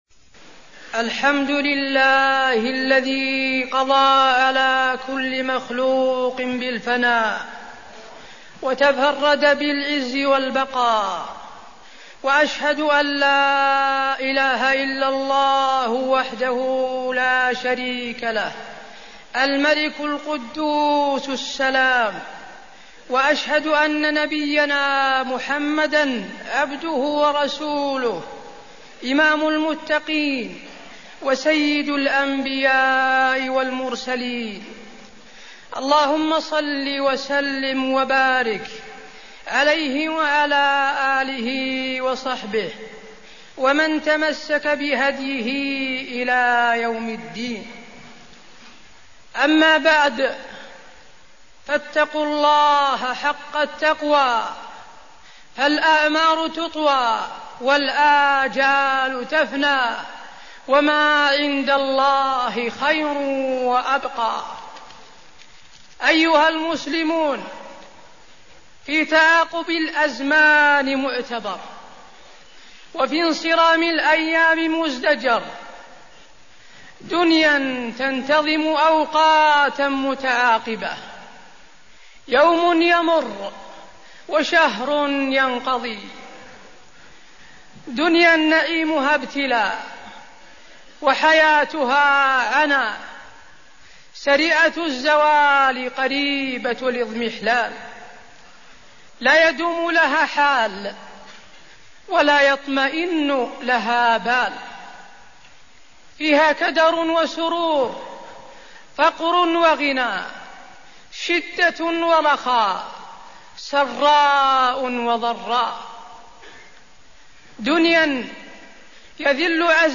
تاريخ النشر ٢ شوال ١٤١٨ هـ المكان: المسجد النبوي الشيخ: فضيلة الشيخ د. حسين بن عبدالعزيز آل الشيخ فضيلة الشيخ د. حسين بن عبدالعزيز آل الشيخ الحث على الاستقامة بعد رمضان